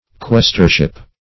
Questorship \Ques"tor*ship\, n. The office, or the term of office, of a questor.